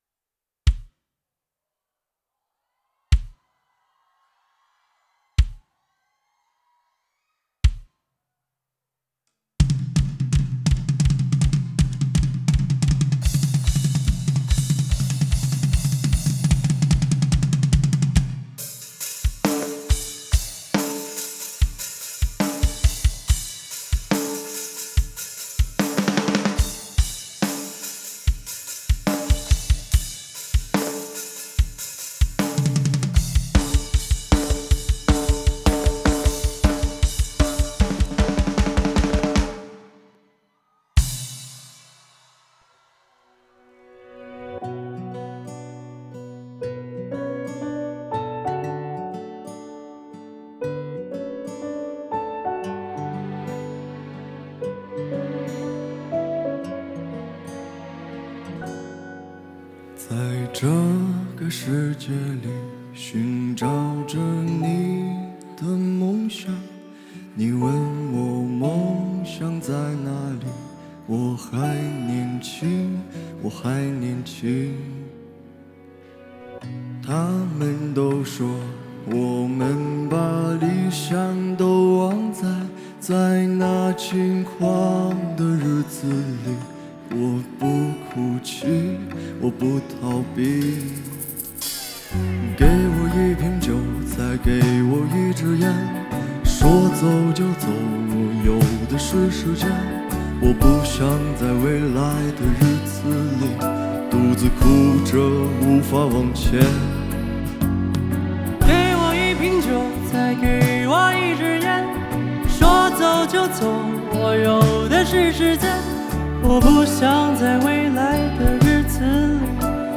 Ps：在线试听为压缩音质节选，体验无损音质请下载完整版
(Live)